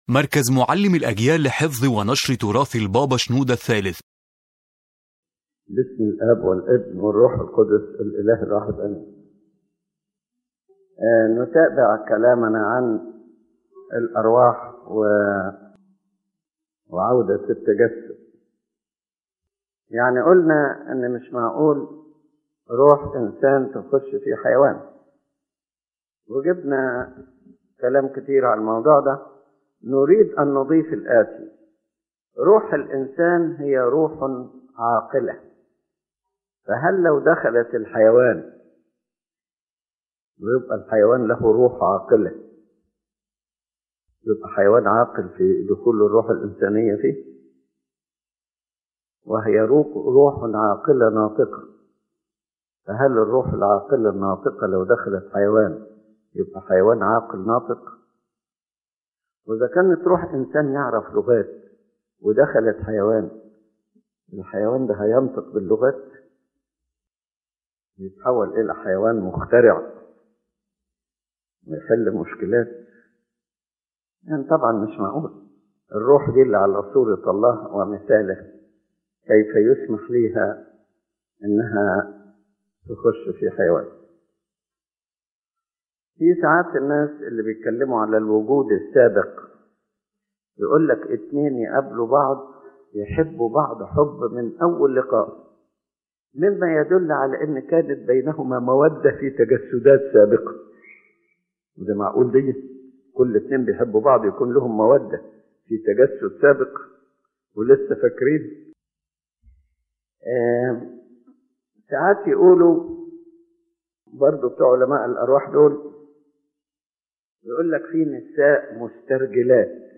The lecture addresses the topic of reincarnation or the return of embodiment, and explains the rejection of this idea from the perspective of the Coptic Orthodox Christian faith. Pope Shenouda III explains that the human soul is a rational soul created in the image of God, and therefore it cannot move to other bodies such as animals or to other persons as some spiritualists claim.